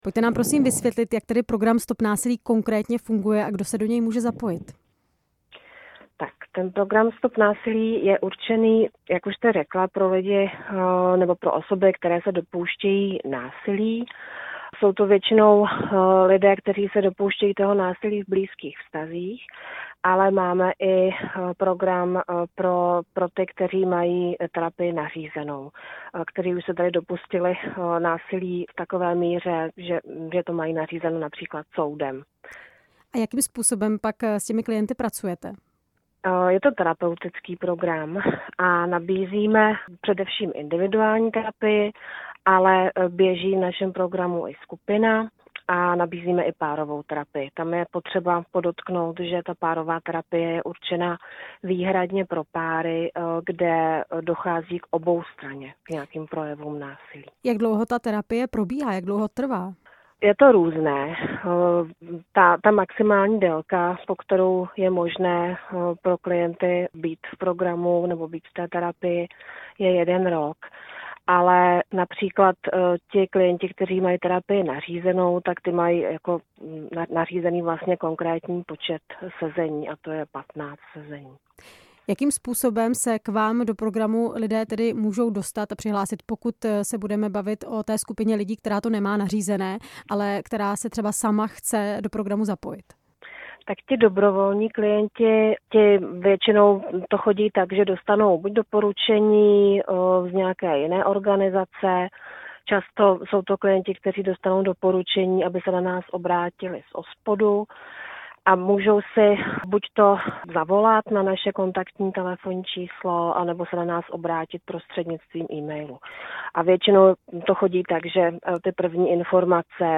ve vysílání Radia Prostor